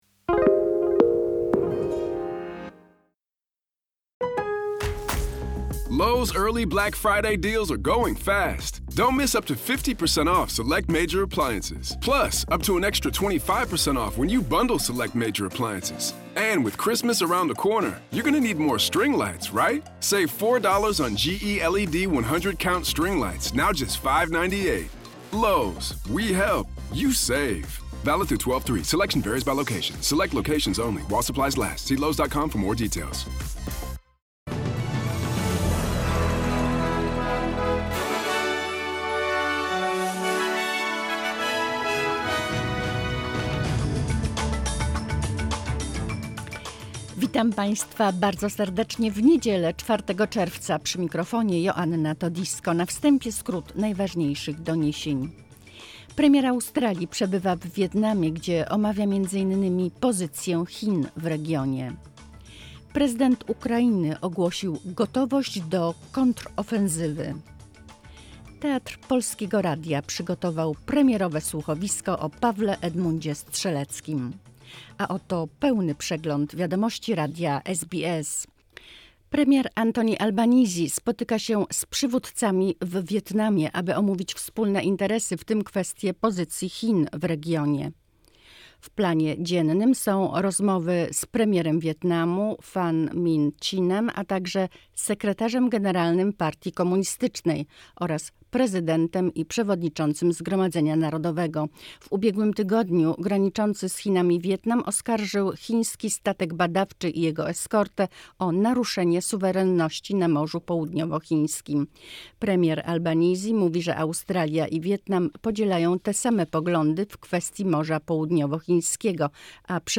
polish news bulletin Source: SBS